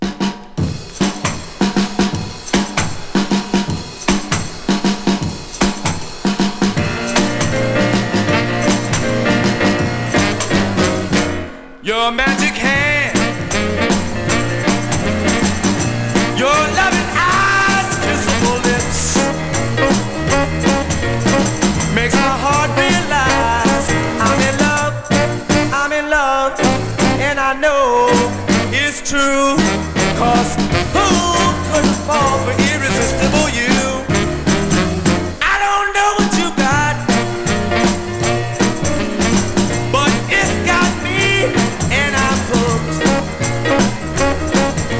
Rhythm & Blues, Rock & Roll 　USA　12inchレコード　33rpm　Mono